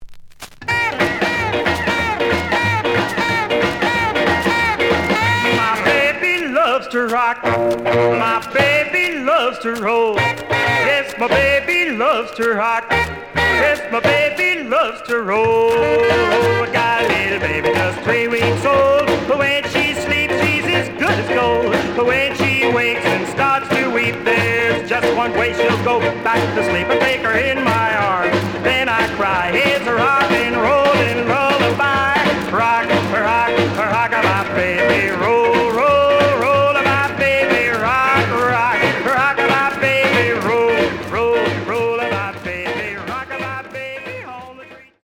The audio sample is recorded from the actual item.
●Genre: Rhythm And Blues / Rock 'n' Roll
Noticeable noise on A side.